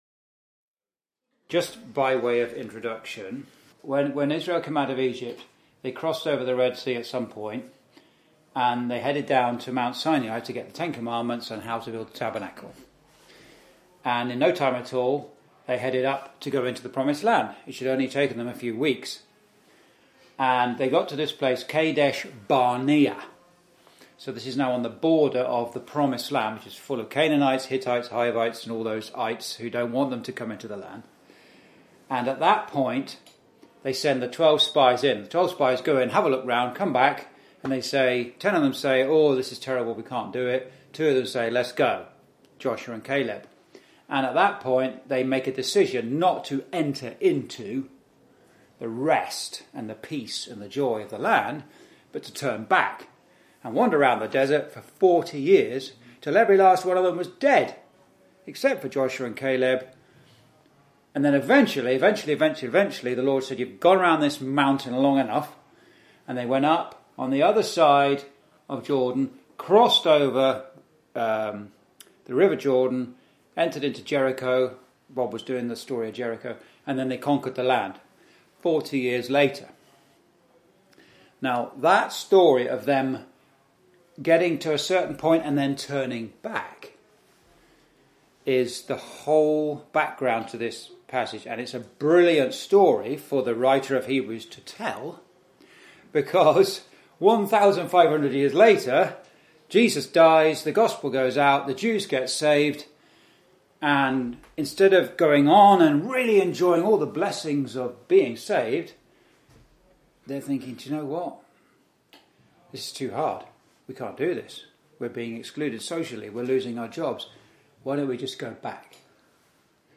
(Message preached in Chalfont St Peter Gospel Hall, 2024)
Verse by Verse Exposition